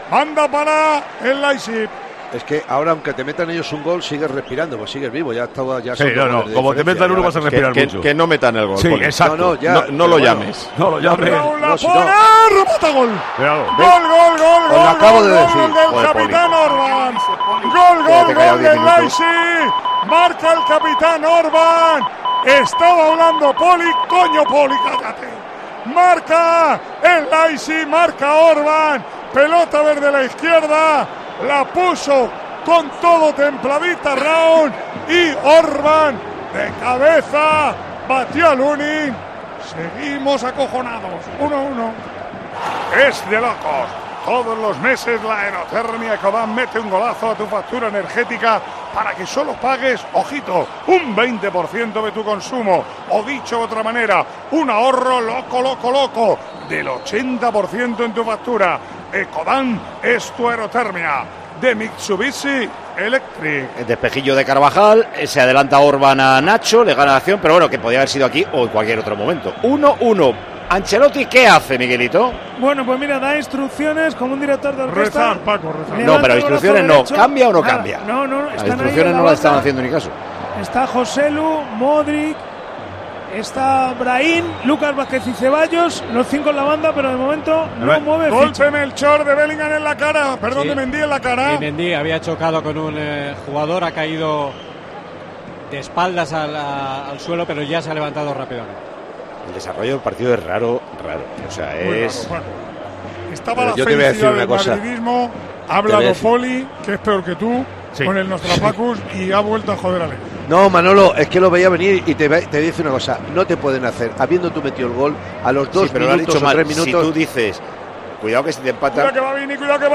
Así vivimos en Tiempo de Juego la retransmisión del Real Madrid - Leipzig
Micrófono de COPE en el Santiago Bernabéu.